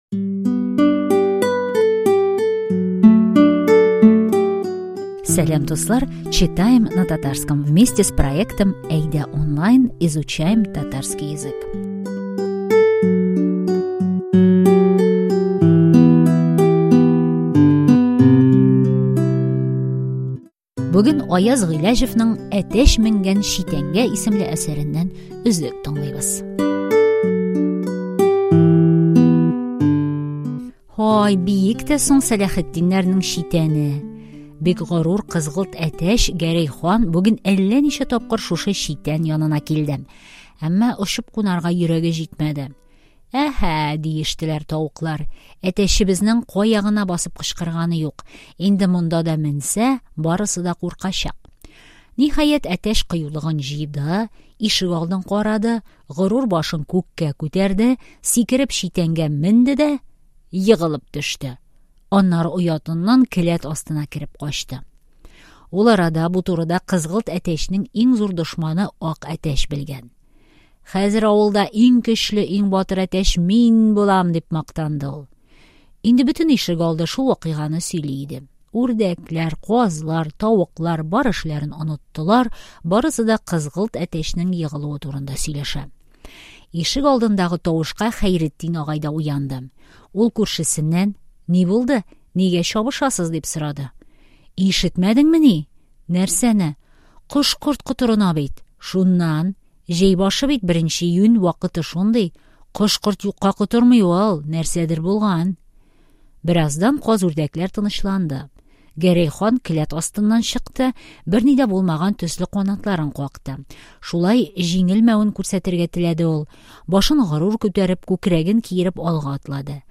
читаем на татарском
Текст отрывка адаптирован и сокращён для изучающих татарский, мы записали к нему аудио, подготовили перевод ключевых фраз и тест по содержанию и лексике.